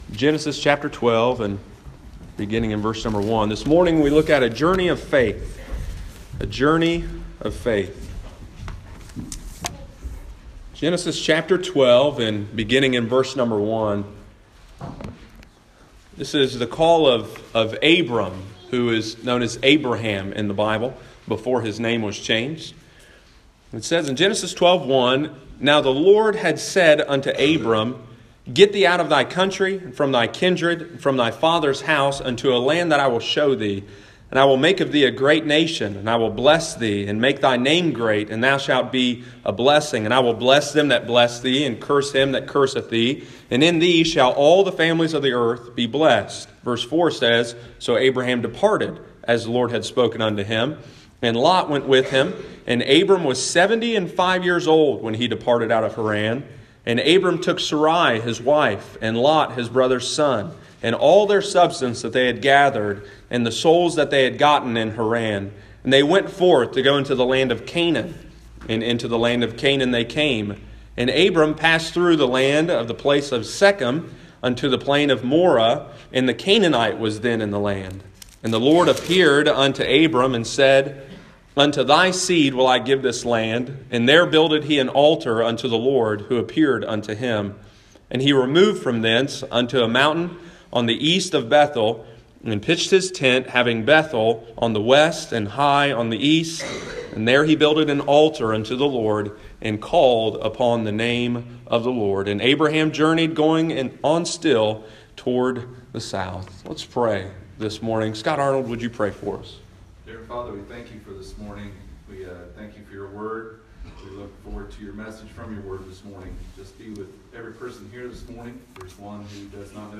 A Journey of Faith: Genesis 12:1-9 – Lighthouse Baptist Church, Circleville Ohio